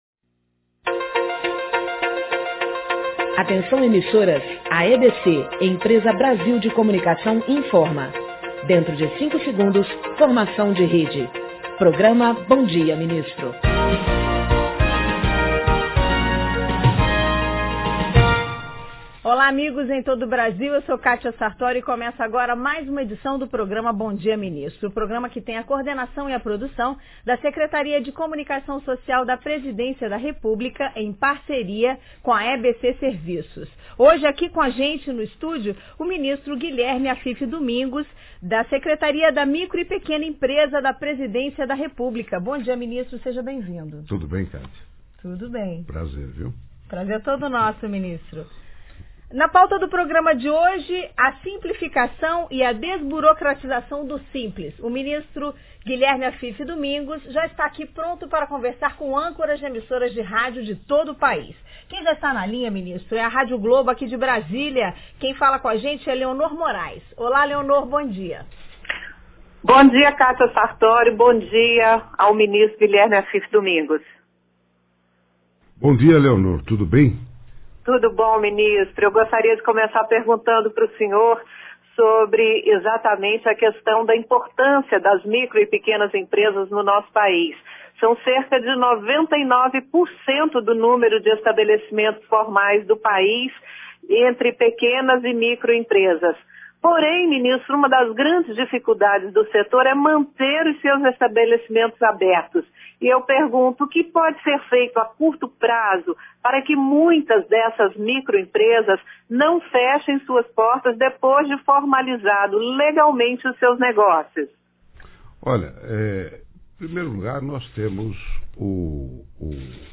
No Bom Dia, Ministro, Guilherme Afif fala sobre a simplificação e desburocratização do Simples
O ministro fala, no programa, sobre a simplificação e desburocratização do Simples, além de dar detalhes sobre o Simples Internacional e o Simples Trabalhista. A entrevista é produzida e coordenada pela Secretaria de Comunicação Social da Presidência da República e transmitida ao vivo pela NBR TV e via satélite, das 8h às 9h.